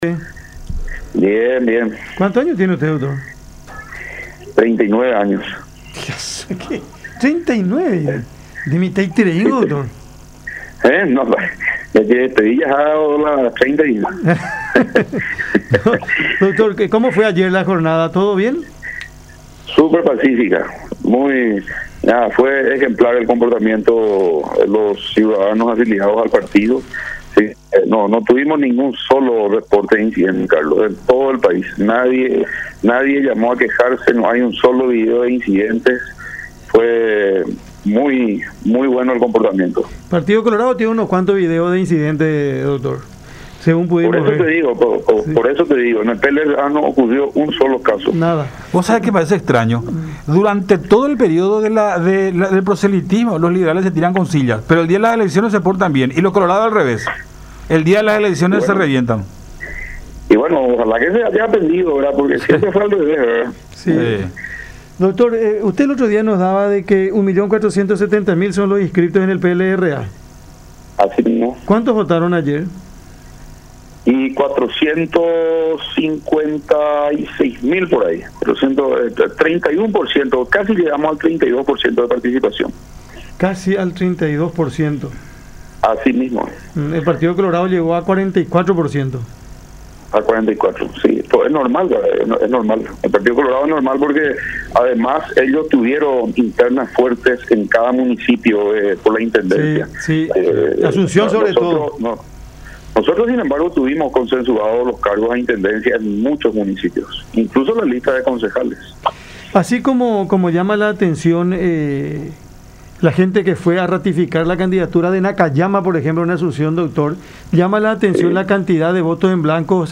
en conversación con Cada Mañana a través de La Unión